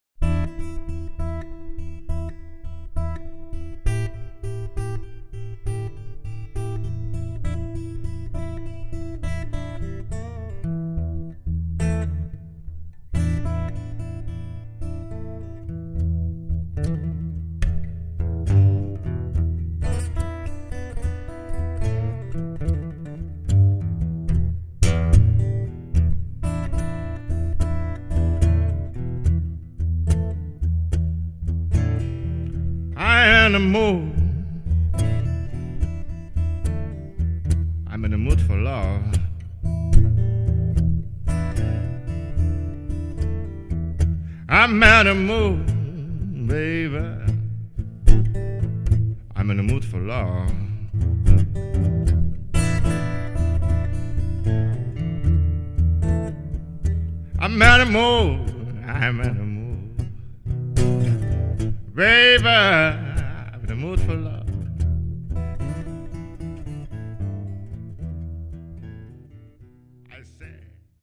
violin
vocal, guitar, dobro, slide
guitar, harmonica, vocal